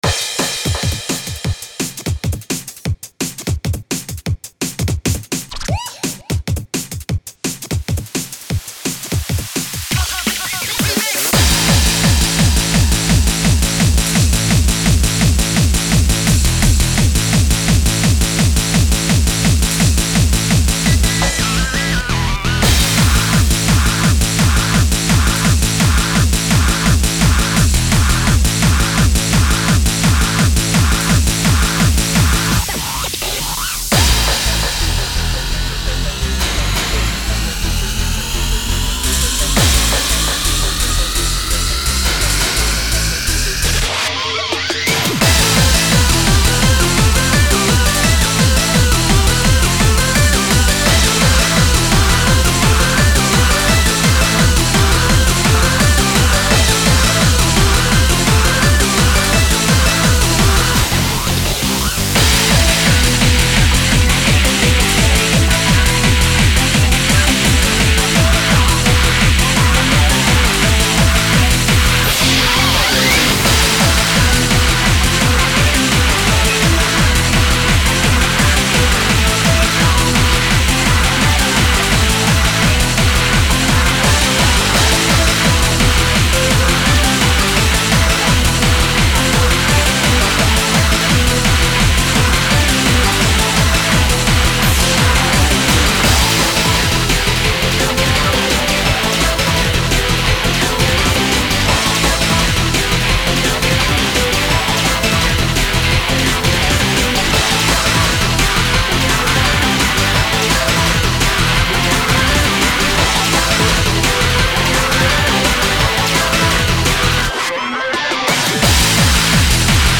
made in the style of old school hands up techno
backing distorted guitar presented in most of the drops
170 bpm